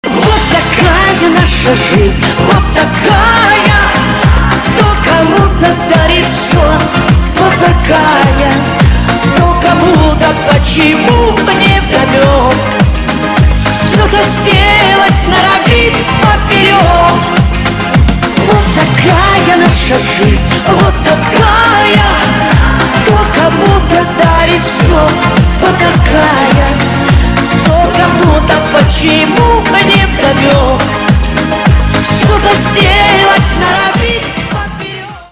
• Качество: 128, Stereo
женский вокал